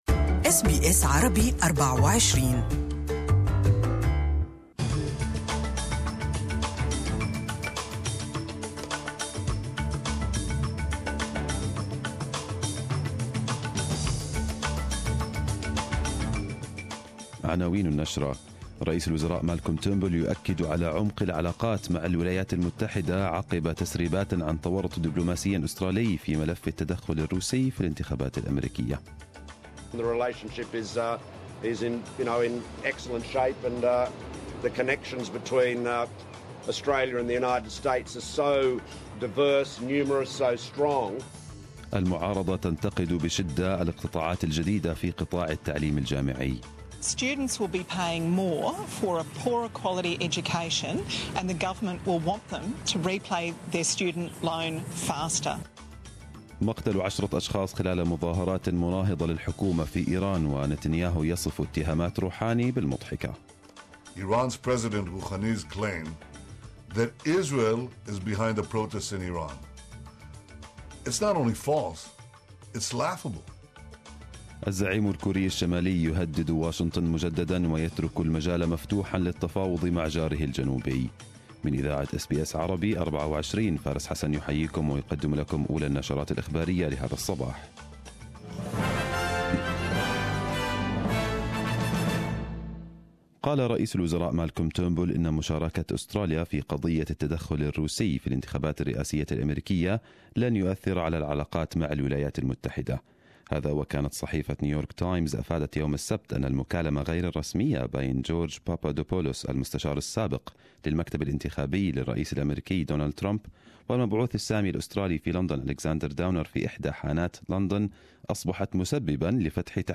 Arabic News Bulletin 02/01/2018